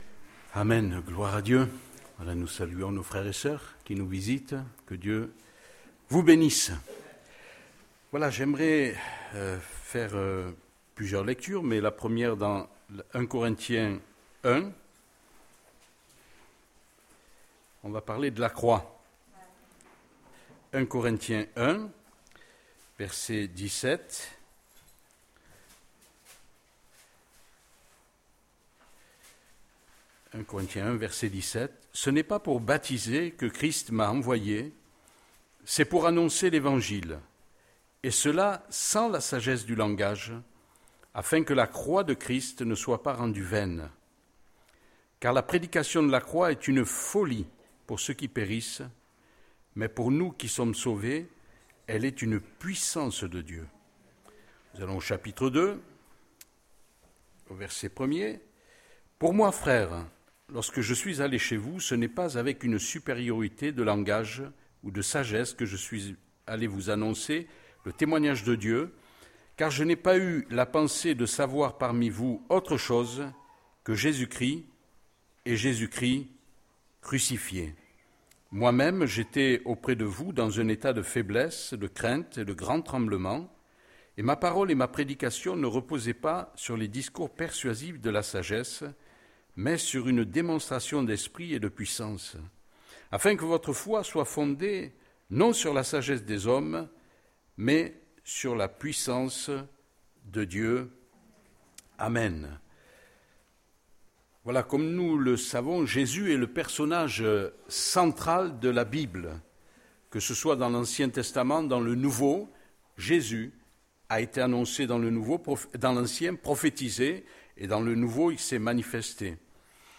Prédication du dimanche 28 juin 2020